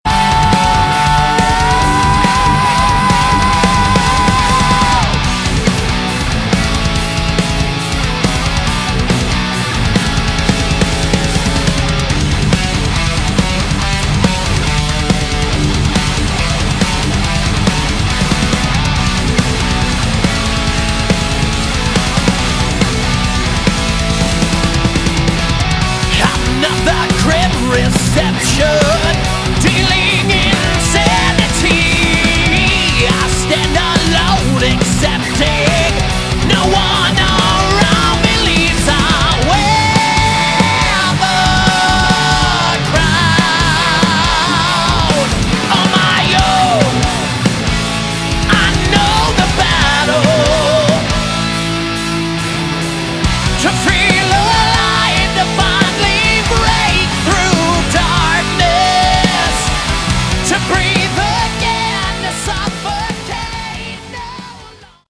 Category: Melodic Metal
lead & backing vocals
Guitars
bass
drums
guitar solo